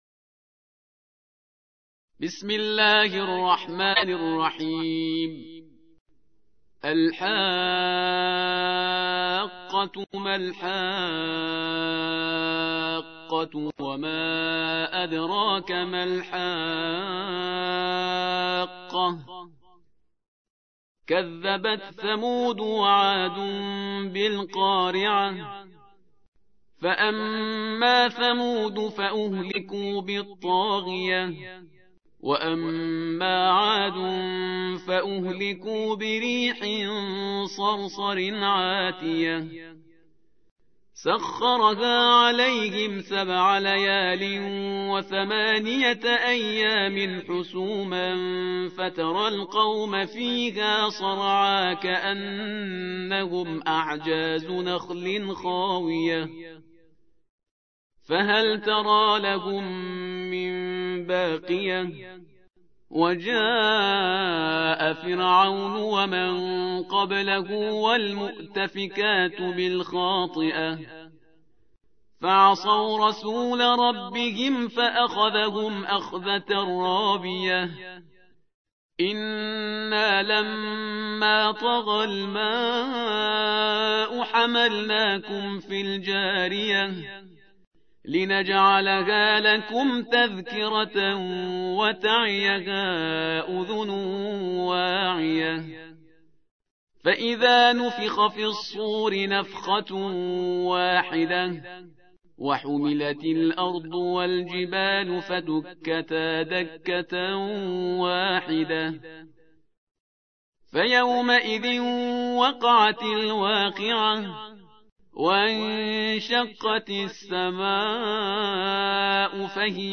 69. سورة الحاقة / القارئ